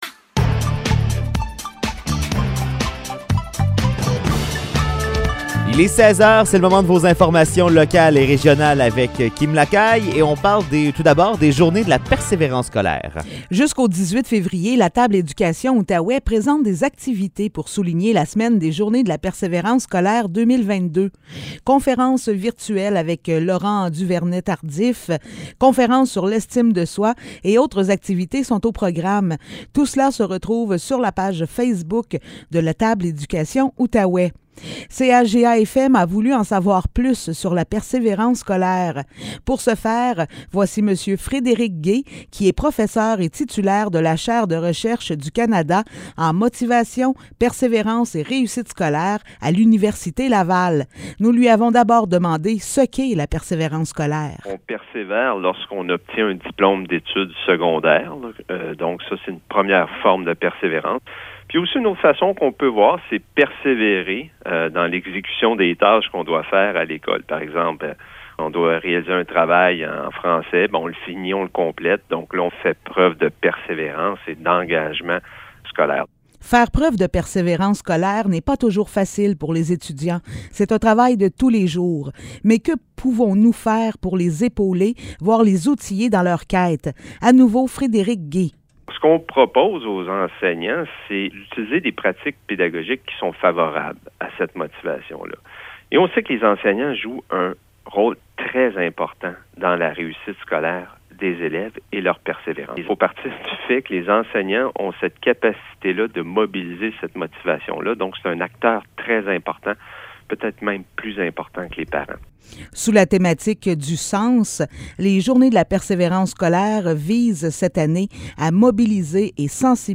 Nouvelles locales - 15 février 2022 - 16 h